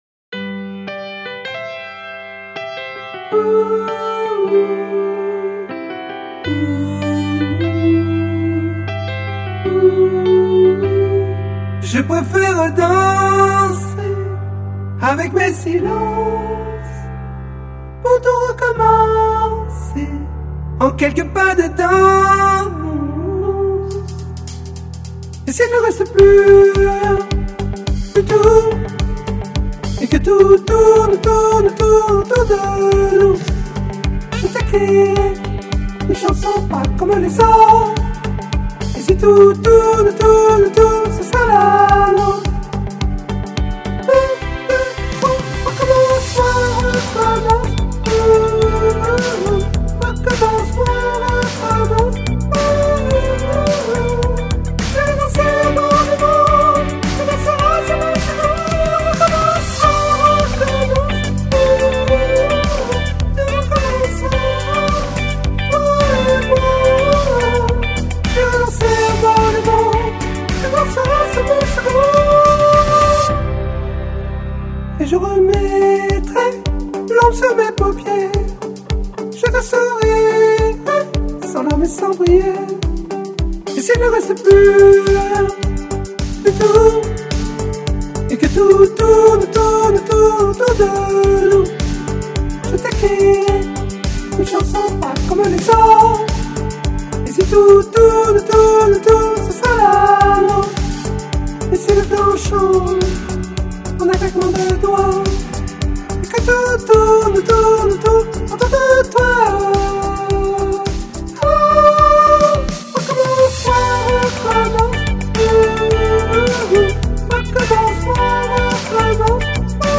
Soprane 01